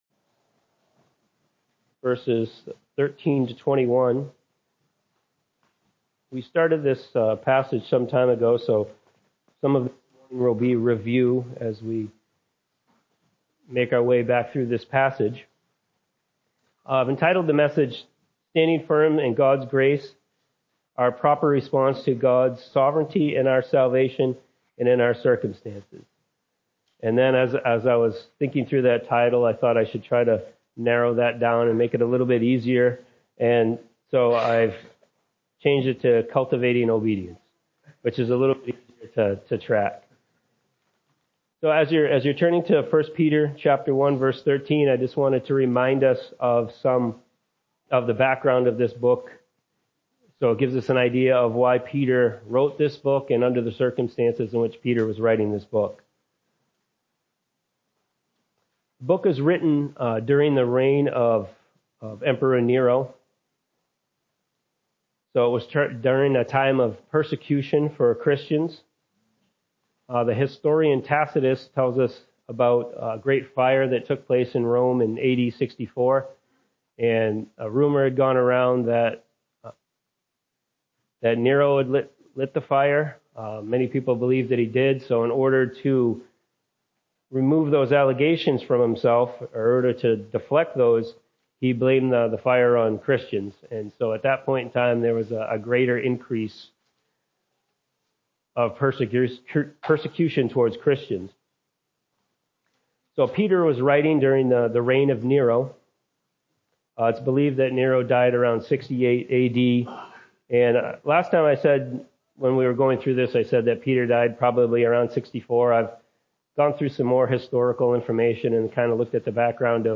A message from the series "Miscellaneous Morning."